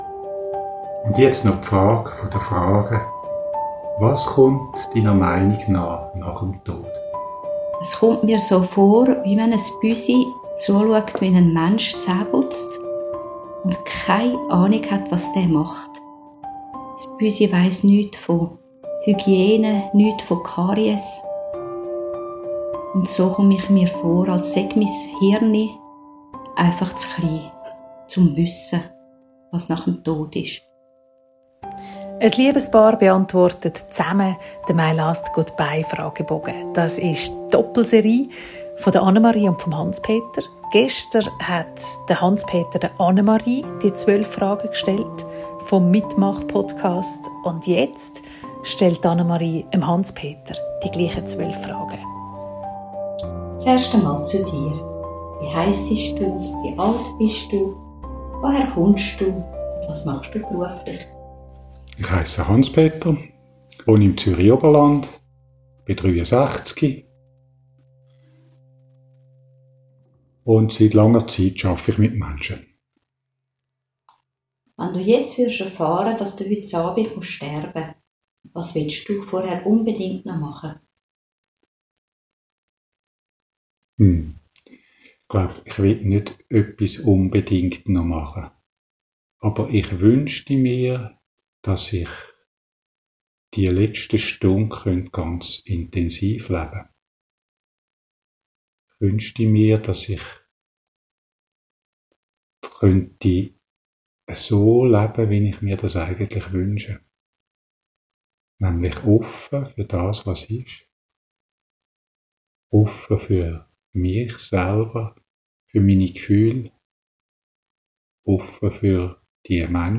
Dabei fliessen Tränen. Es wird geschwiegen. Es werden Gedichte rezitiert.